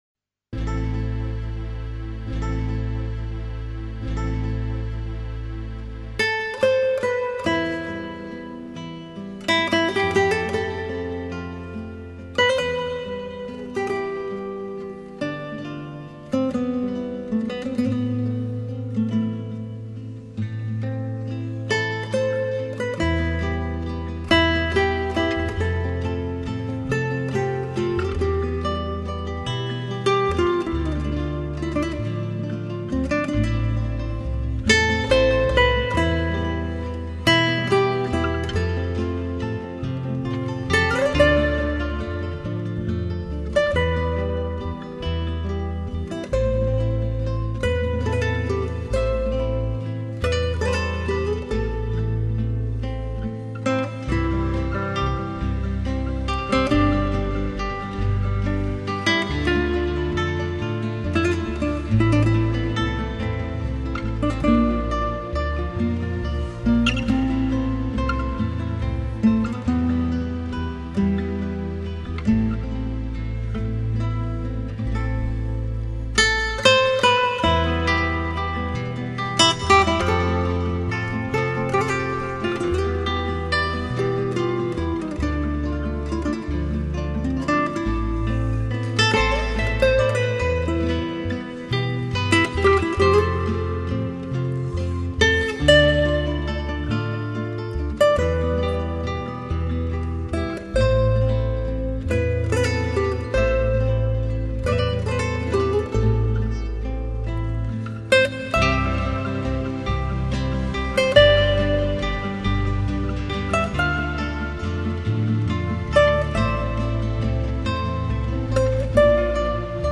西班牙吉它热情高贵、骄傲坦然、 极赋活力，又有忧郁的冥思。